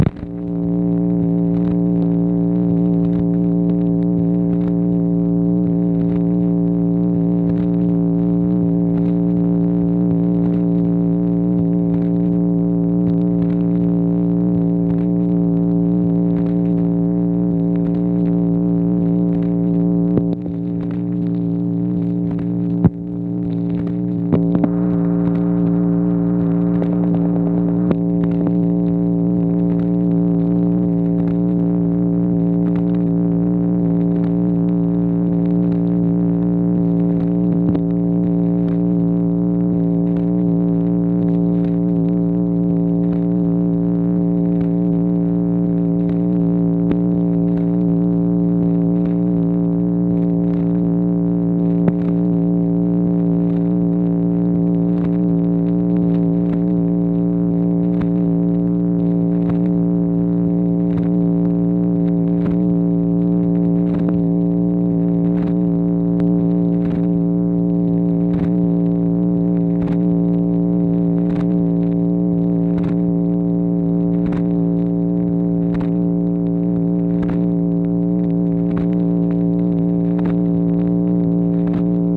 MACHINE NOISE, June 12, 1964
Secret White House Tapes